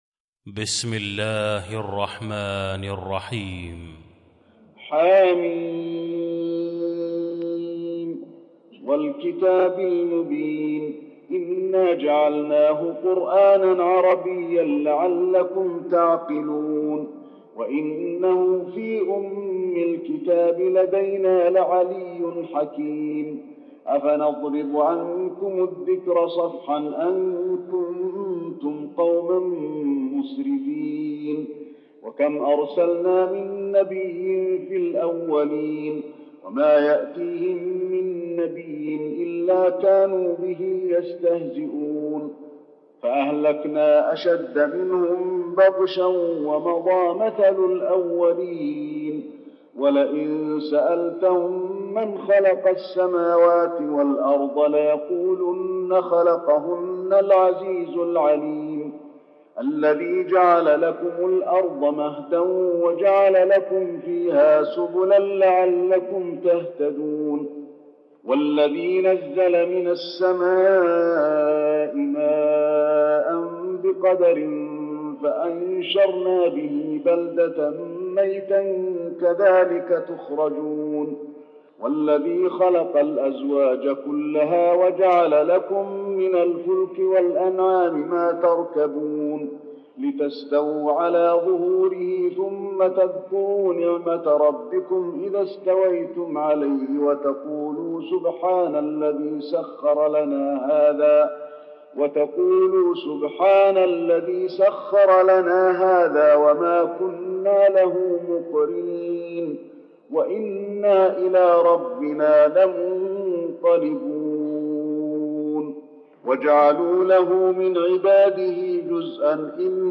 المكان: المسجد النبوي الزخرف